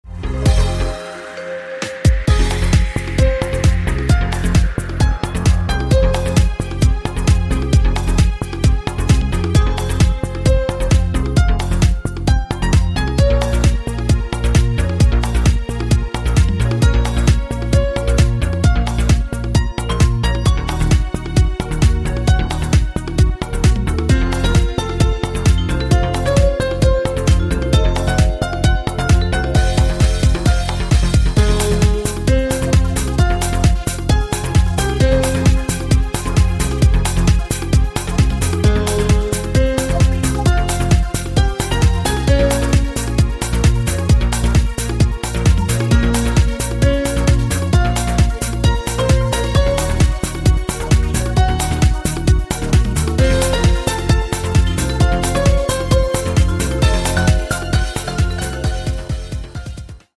Клубные Рингтоны » # Рингтоны Без Слов